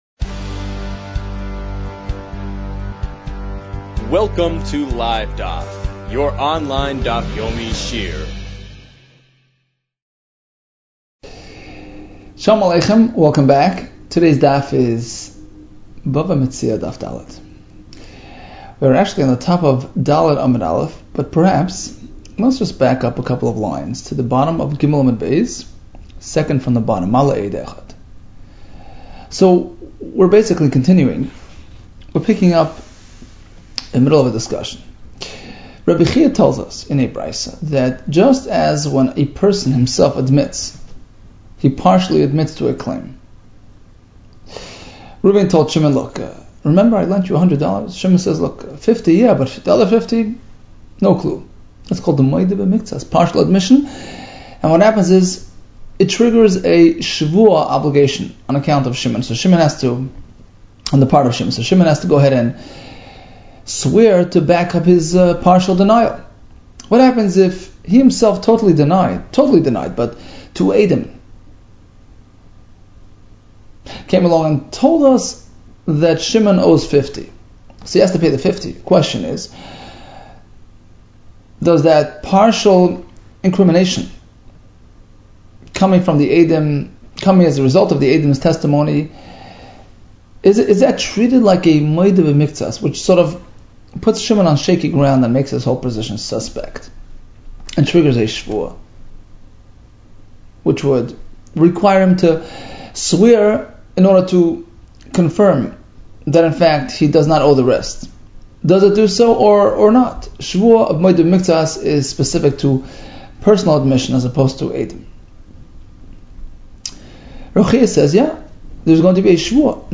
Bava Metzia 4 - בבא מציעא ד | Daf Yomi Online Shiur | Livedaf